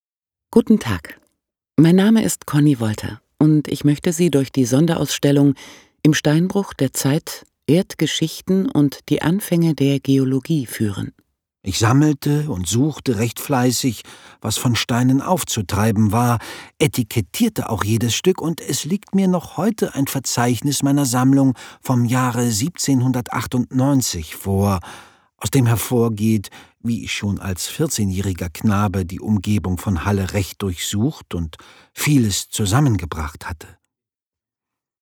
Audioguide „Im Steinbruch der Zeit. Erdgeschichten und die Anfänge der Geologie“